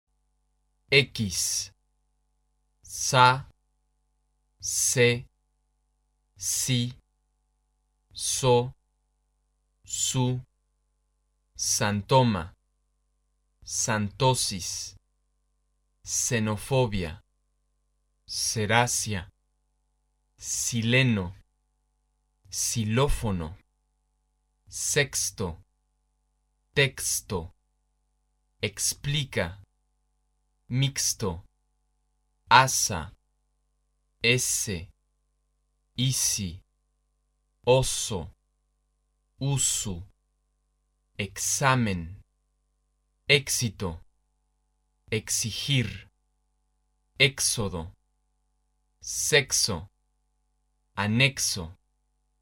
x出现在字母或辅音之前时发s音，出现在两个元音之间时发[xs]音。目前的倾向是在任何情况下都发[xs]